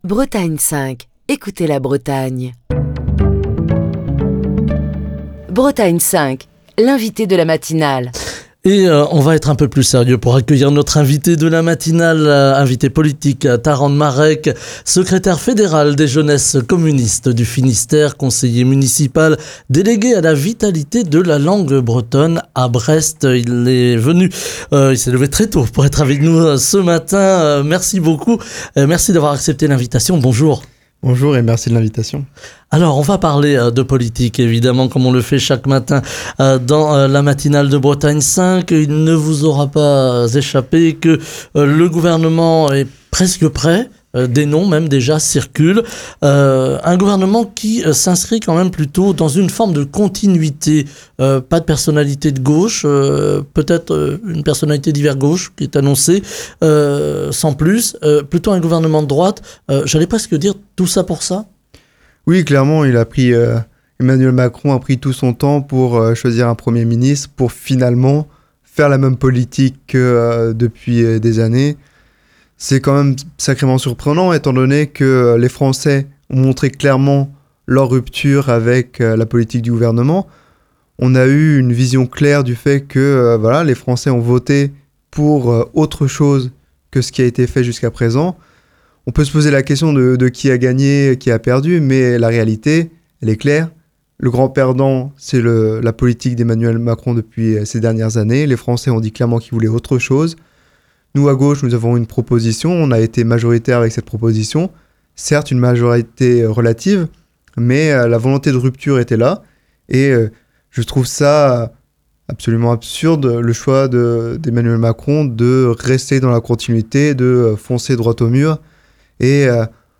Ce vendredi dans la matinale de Bretagne 5, nous recevons Taran Marec, secrétaire fédéral des Jeunesses communistes du Finistère, conseiller municipal délégué à la vitalité de la langue bretonne à Brest, pour évoquer l'actualité politique, mais également les difficultés rencontrées en Bretagne par les écoles Diwan.